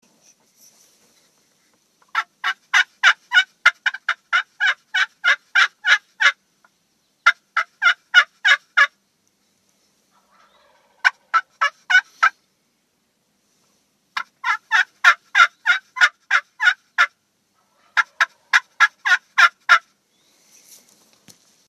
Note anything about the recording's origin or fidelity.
The sound files below were recorded outside during hunting situations. Sound volume is much better.